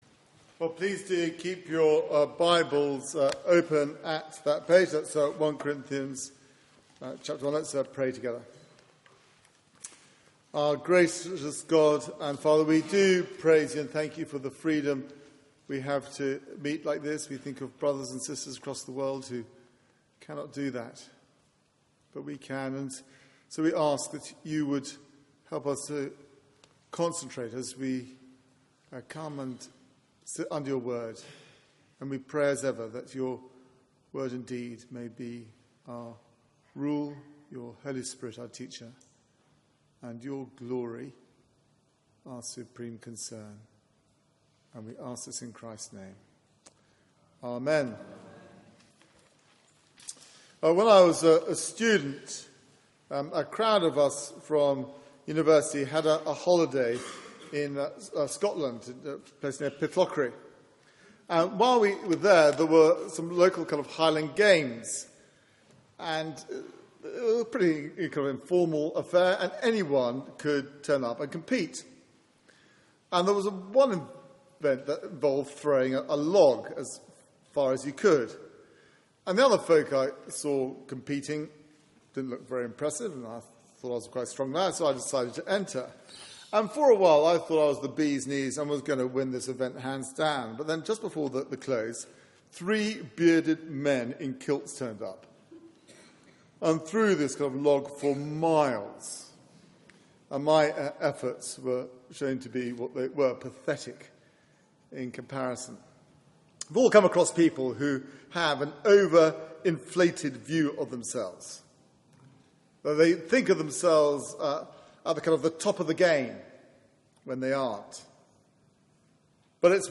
Media for 9:15am Service on Sun 19th Oct 2014
Sermon